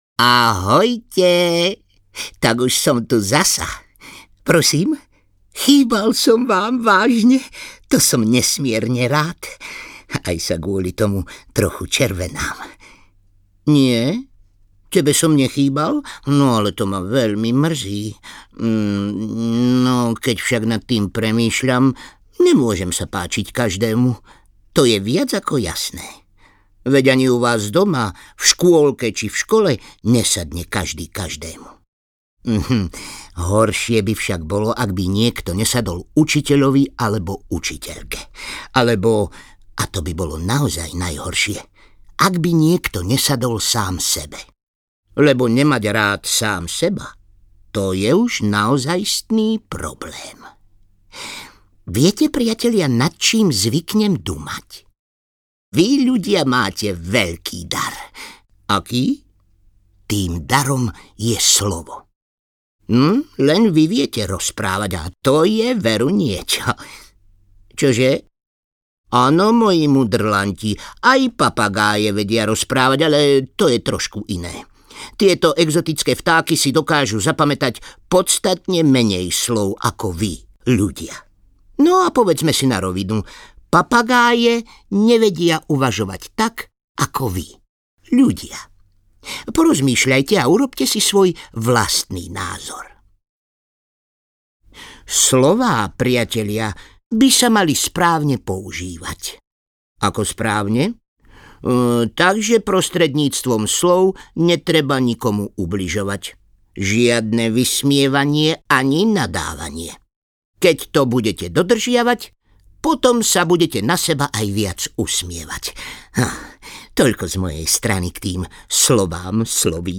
Rickiho dobrodružstvá audiokniha
Ukázka z knihy